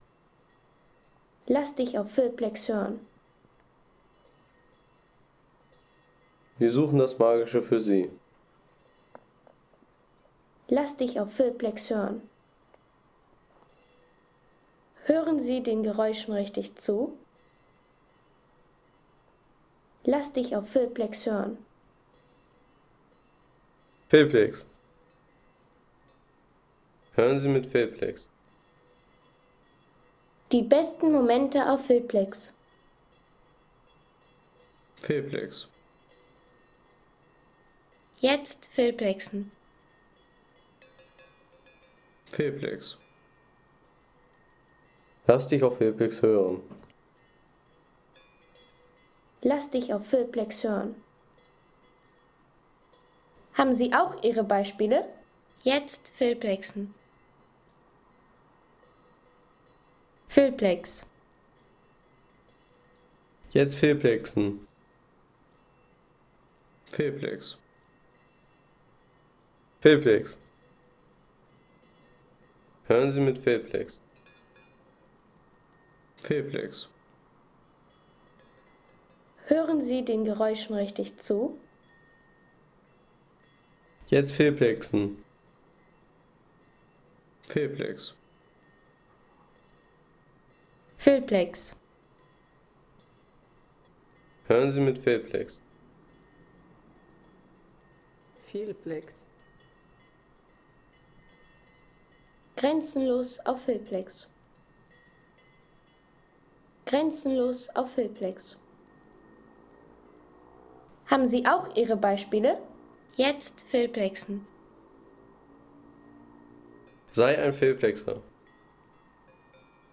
Weidelandschaft
Sommeridylle in der Tiroler Weidelandschaft – Klang der grasenden K 3,50 € Inkl. 19% MwSt.